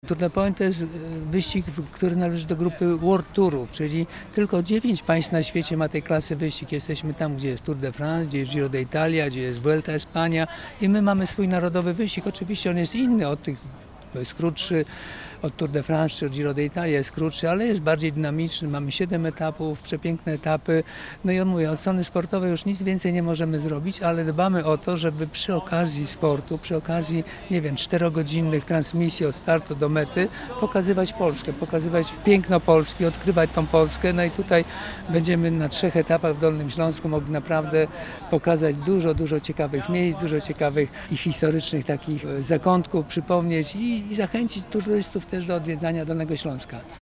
Tour de Pologne - konferencja prasowa
Tour de Pologne należy do światowej klasy world-tourów. Tylko 9 państw na świecie organizuje takie wydarzenia, dodaje organizator Czesław Lang.